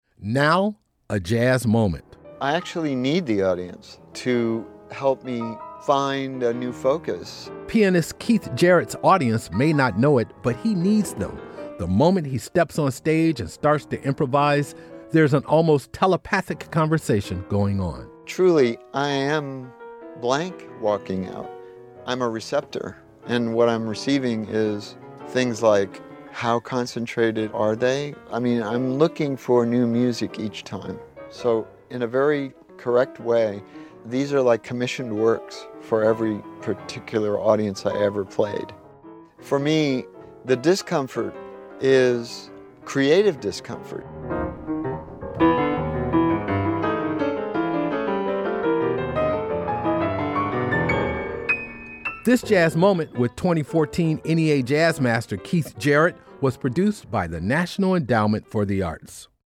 Excerpt of "Part XV" from the album, Rio, composed and performed by Keith Jarrett, used courtesy of ECM Records.